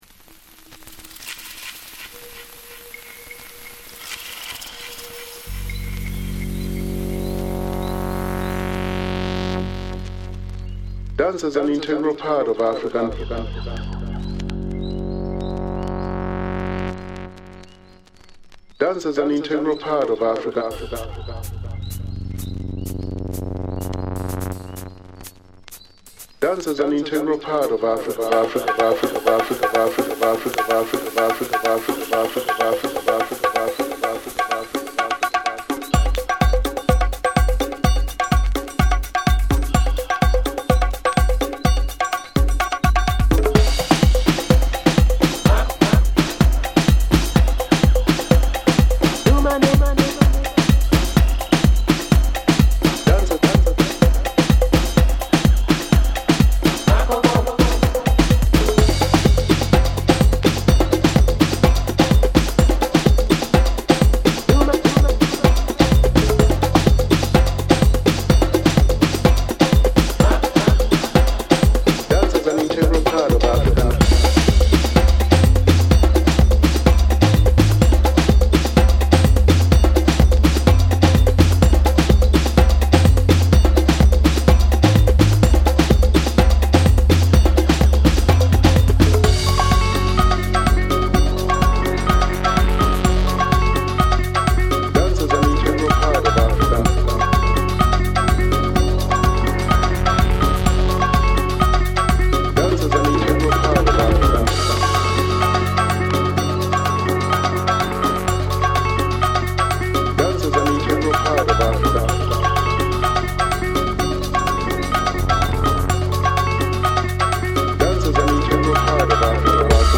怒涛のパーカッションから入るアフロトラック